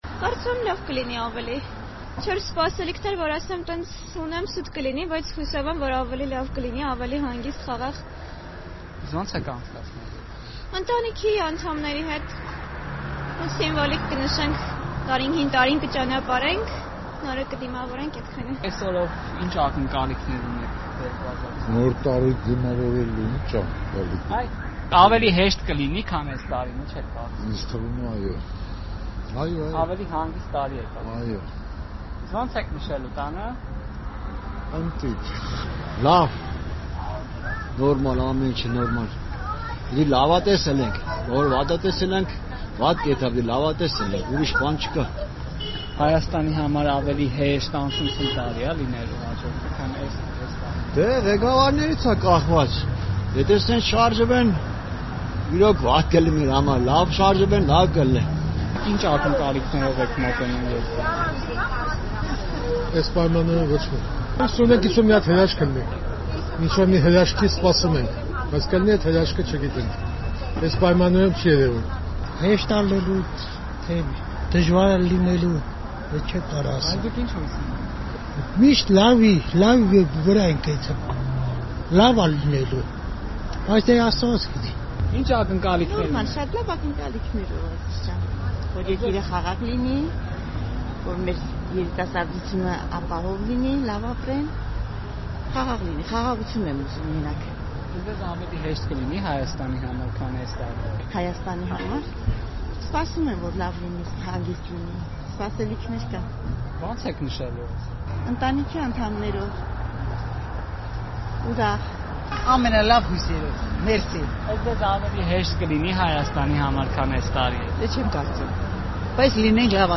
Մի քանի ժամից Հայաստանը հրաժեշտ կտա 2021 թվականին։ Ի՞նչ տրամադրությամբ, ի՞նչ ակնկալիքներով են մարդիկ դիմավորում գալիք 2022 թվականը։ «Ազատությունը» հարցում է անցկացրել Երևանի փողոցներում։